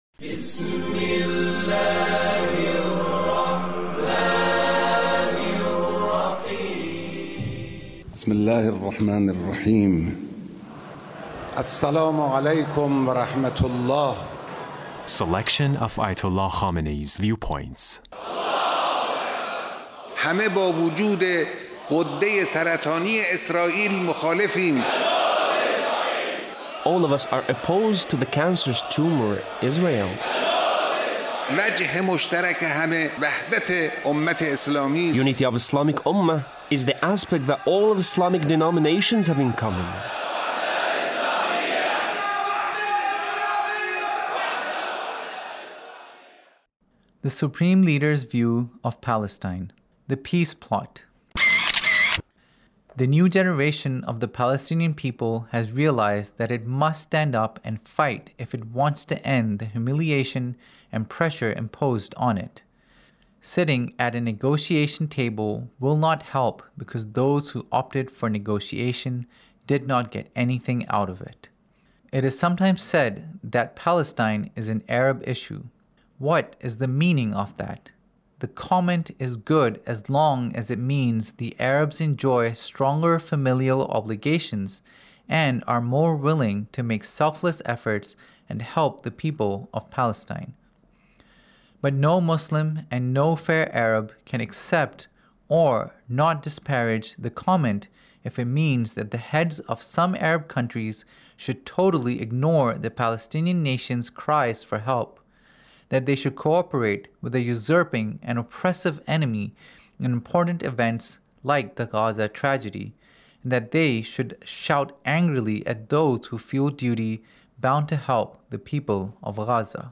Leader's Speech on Palestine